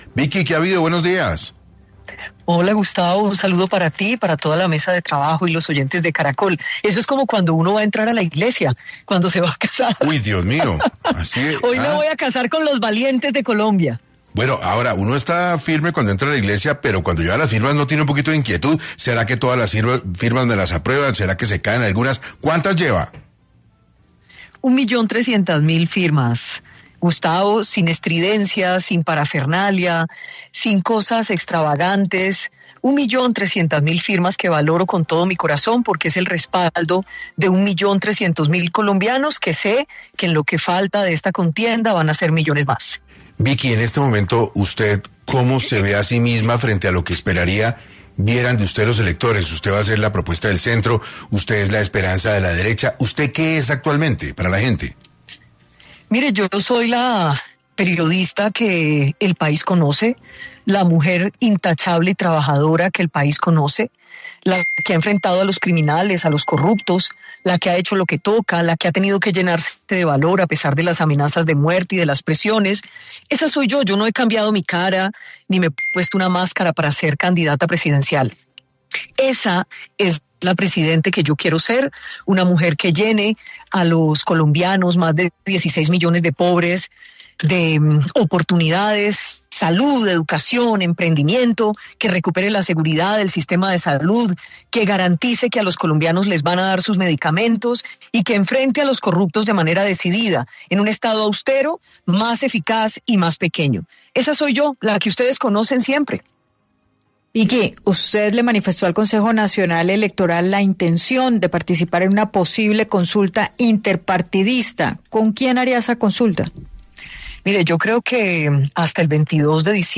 La candidata Vicky Dávila habló en 6AM del futuro de su trabajo en la política